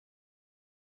Polarity Test Twisted: In this test , either the sound should come from left or right speakers or from  beyond the outside boundaries of the speakers.
Polarity Test Twisted
pinkoutofphase.wav